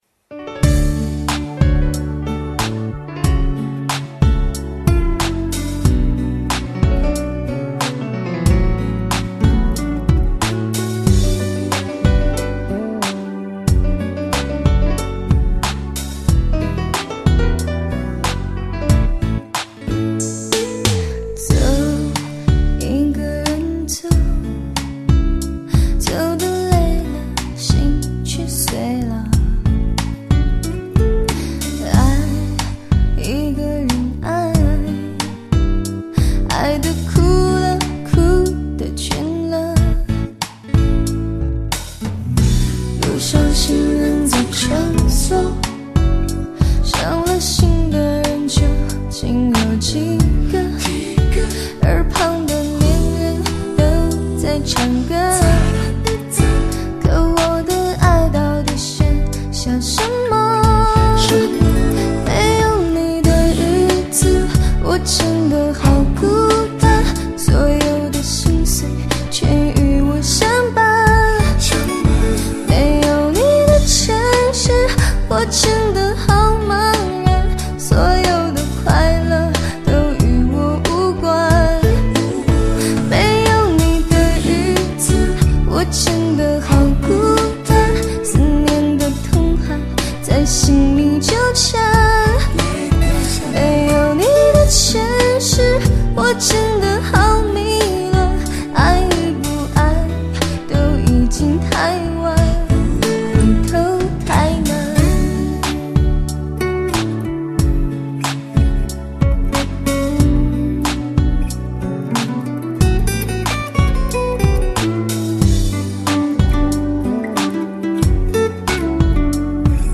第一个的感觉便是这声音好纯净，有着在青藏高原才独有的天籁声线，象青海的天一样的清澈。